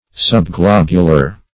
Subglobular \Sub*glob"u*lar\, a. Nearly globular.